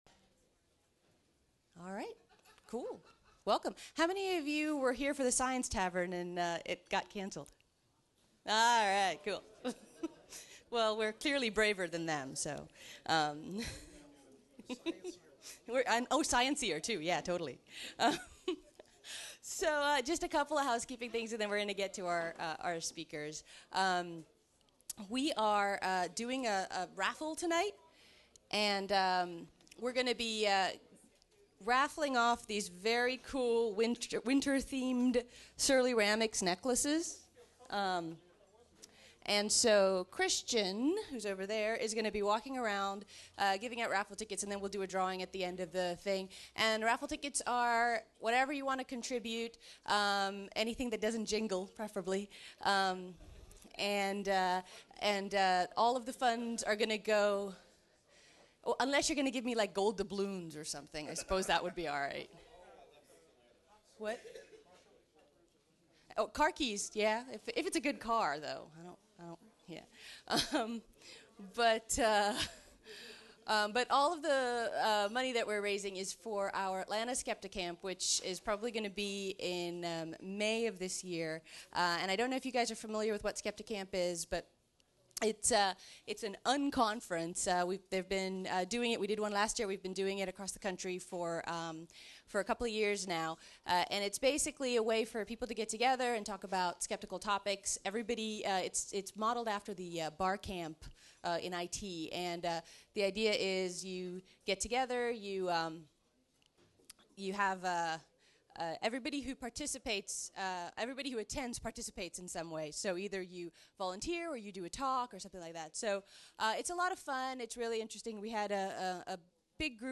Every so often, we may post audio of our public talks.